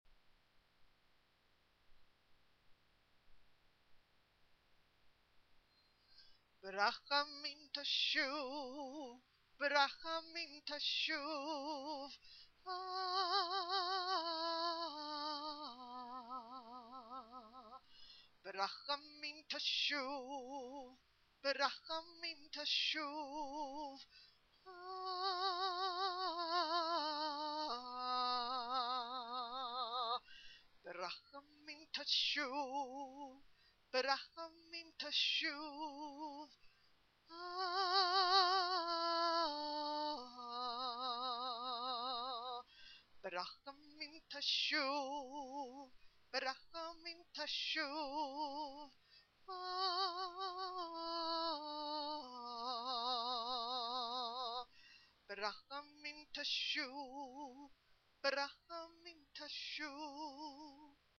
I composed this chant in preparation for a trip to Israel.